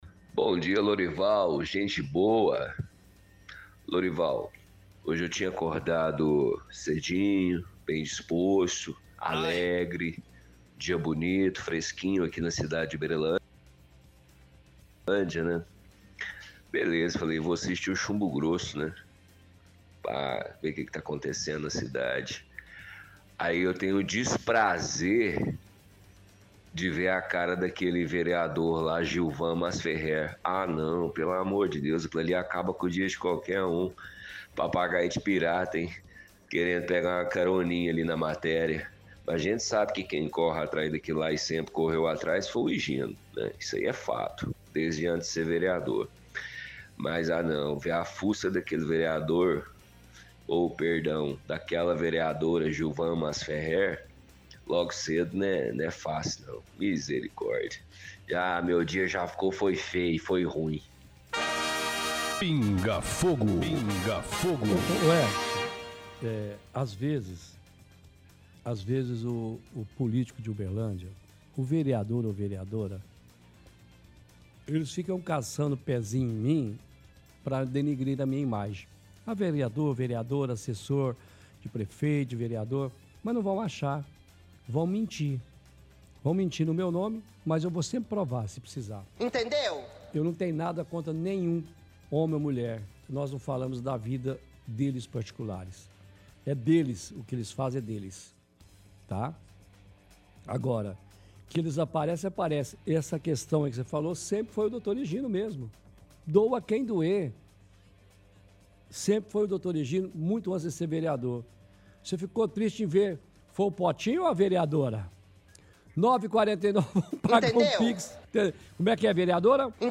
– Ouvinte critica aparição da vereadora Gilvan na matéria do Chumbo Grosso sobre a falta de energia no assentamento Maná.
– Caçoa da vereadora com áudios antigos.